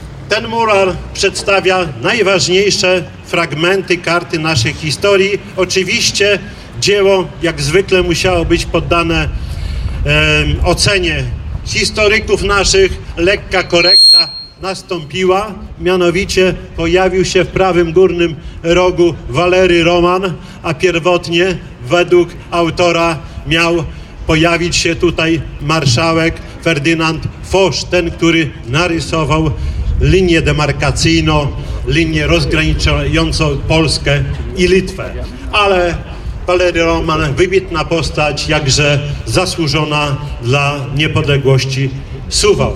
Mówi Czesław Renkiewicz, prezydent Suwałk.